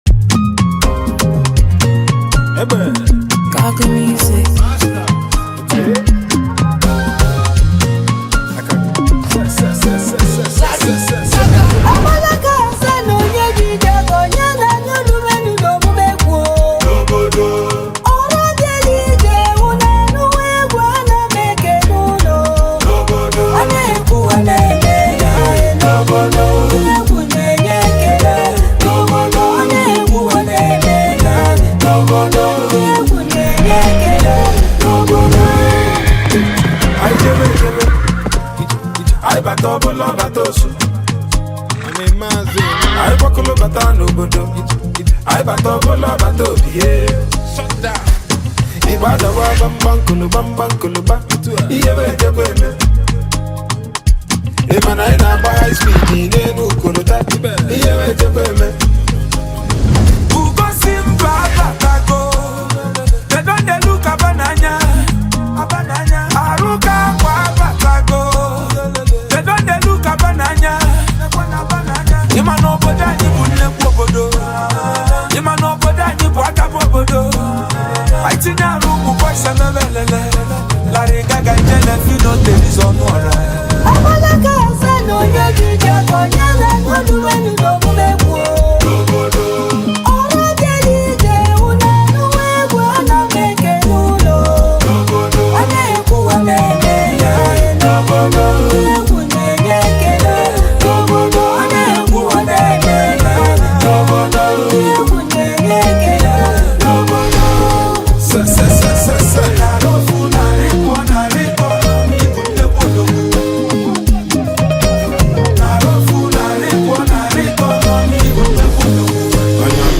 highlife track
highlife tune